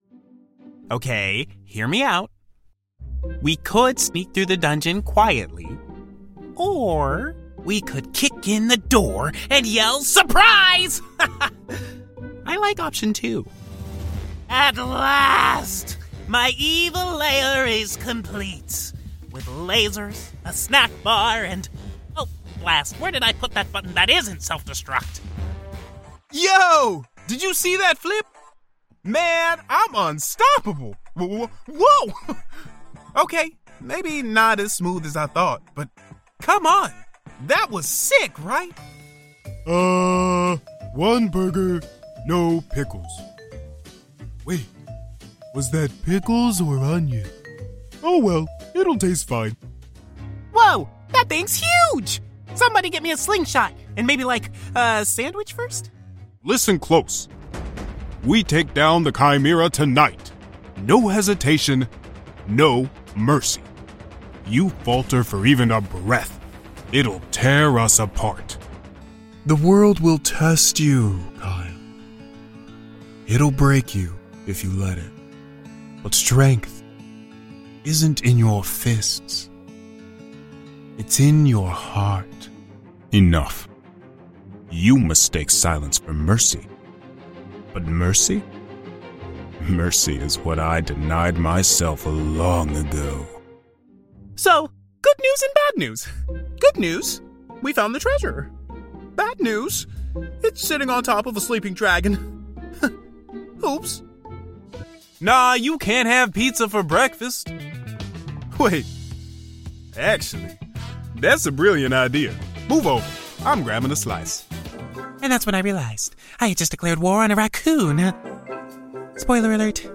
Animation
AAVE, General English(British), US Southern, Transatlantic